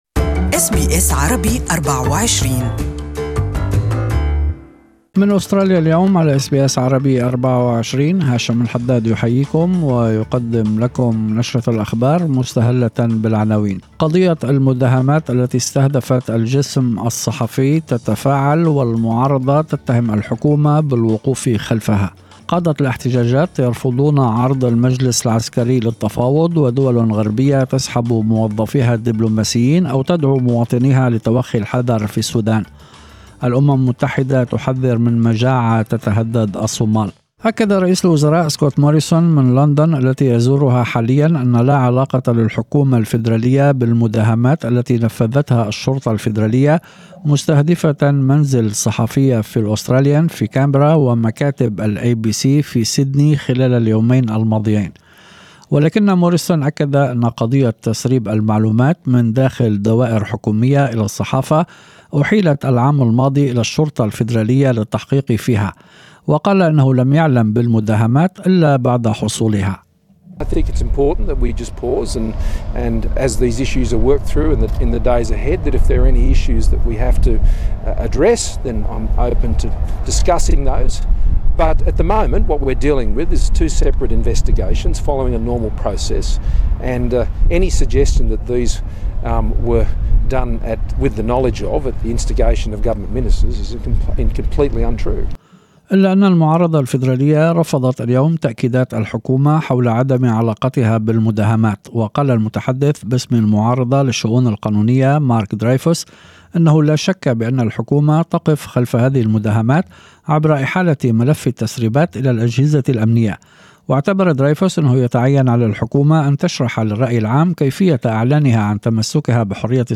أخبار المساء: الجهل الغذائي يسمّم الأستراليين